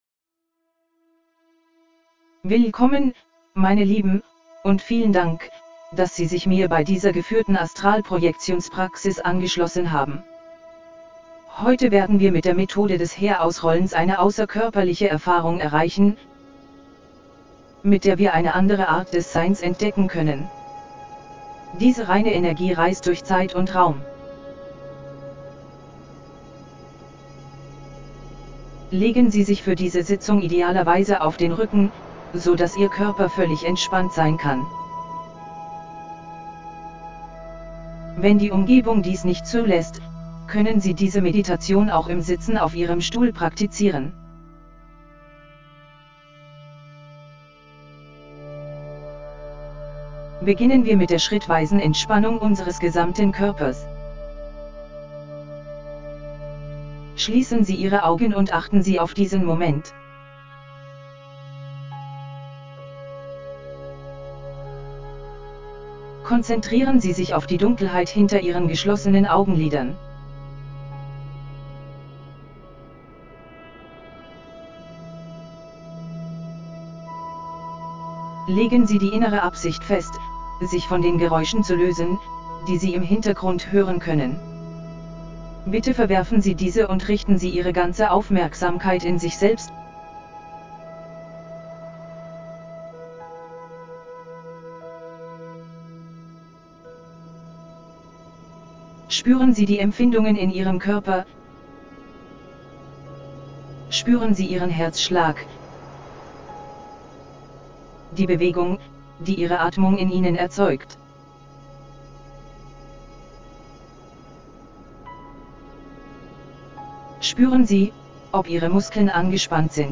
Geführte Astralprojektions-Meditation
Geführte Astralprojektions-Meditation und Hypnose für außerkörperliche Erfahrungen Willkommen zu dieser geführten Astralprojektion, liebe Seele.
OBEAstralProjectionGuidedAstralProjectionMeditationHypnosisDE.mp3